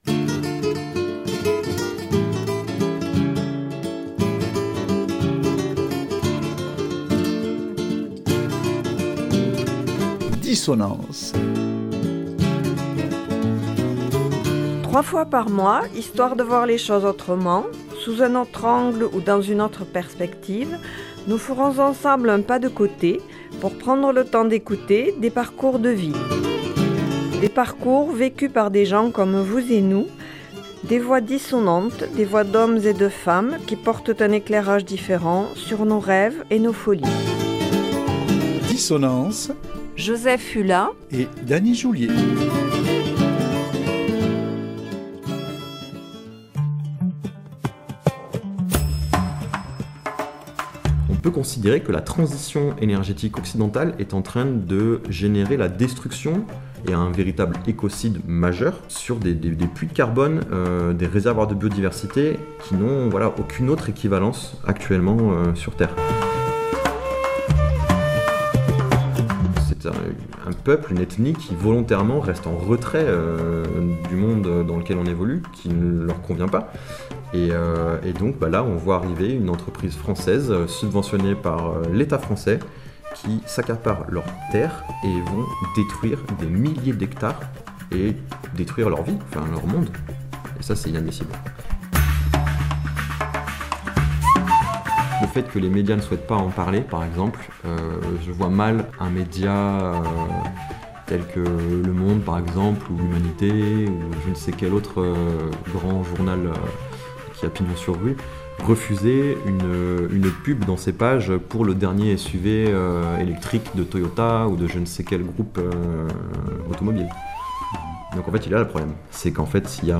Nous le retrouvons, entre deux aéroports, où, indigné, il revient du Sulawesi en Indonésie.